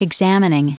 Prononciation du mot examining en anglais (fichier audio)
Prononciation du mot : examining